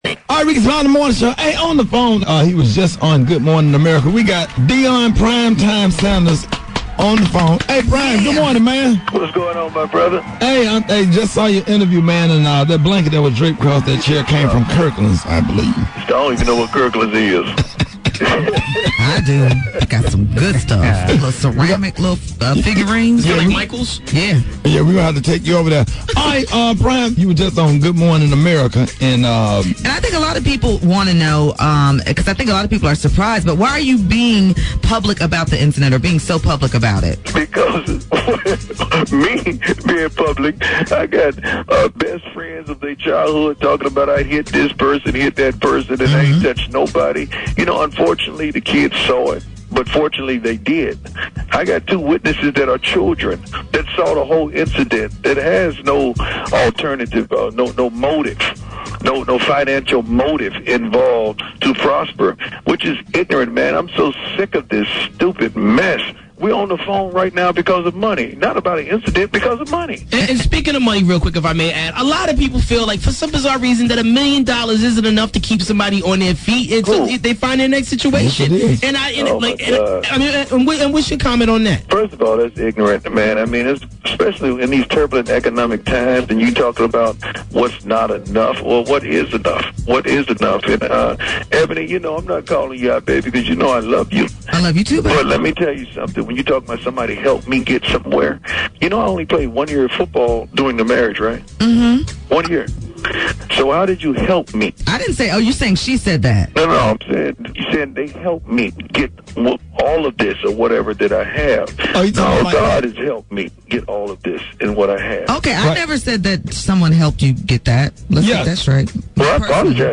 RSMS Interviews
Tracey Edmonds Relationship [EXCLUSIVE INTERVIEW]